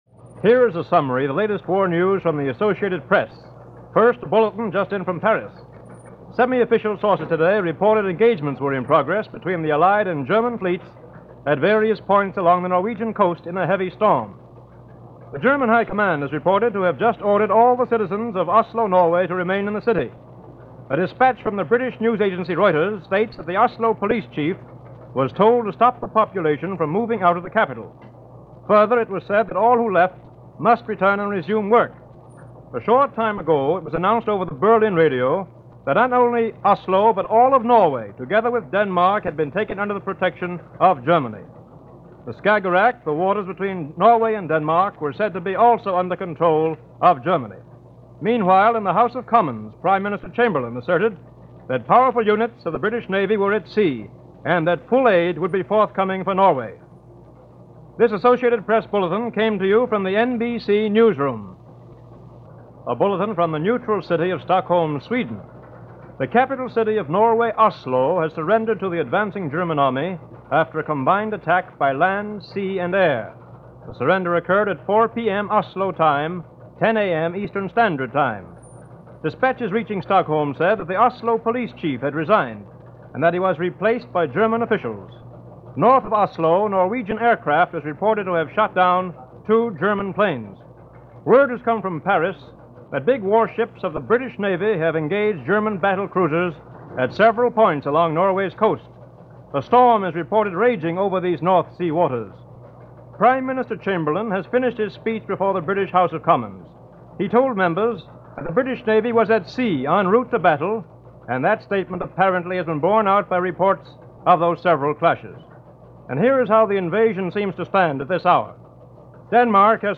News for this day in 1940 was mostly bulletins, as the War in Europe entered a new phase – the German invasion of the Scandinavian countries. First to fall under invasion was Sweden, with Norway close behind, as German troops in transport planes were landing in Oslo Airport.
Here is a capsule of those reports given throughout the day via The Blue Network of NBC Radio on April 9, 1940.